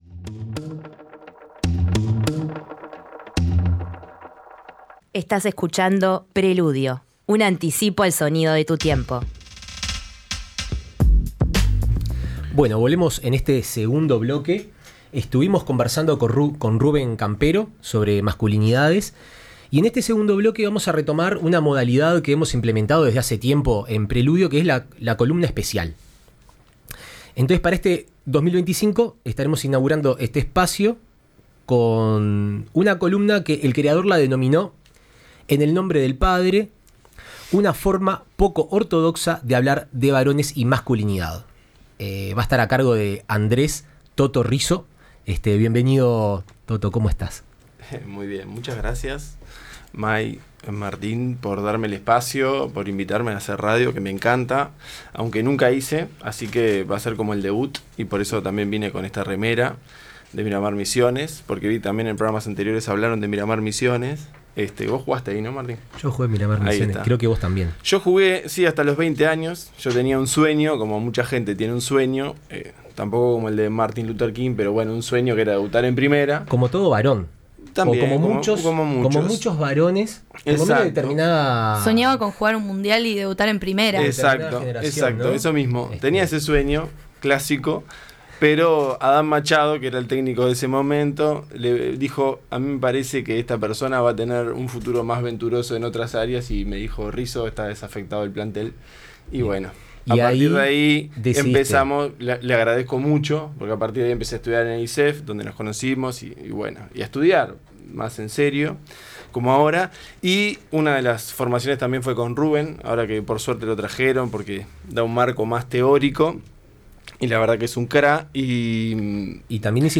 Entrevista al licenciado en psicología por la UdelaR